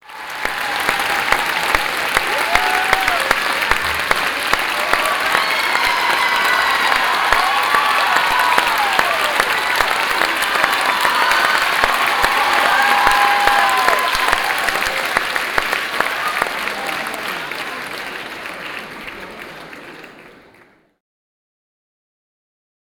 Крики, свист и аплодисменты толпы, хлопающей в ладоши победителю